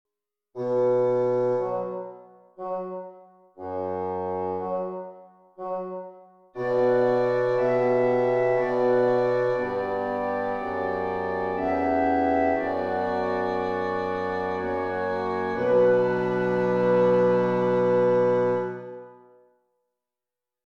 4.8 Dictations
Dominant Preparations Circle Progressions